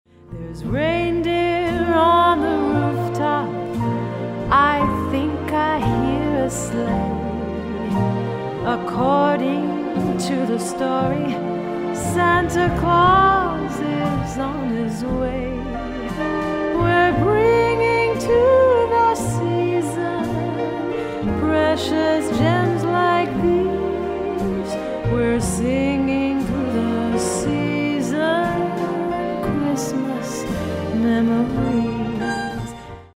complete with the classic clarinet-led reed section.
Saxes are CAATT. Vocal range is F3 to C4.
Big Band
Swing Era
quarter note = 70
Female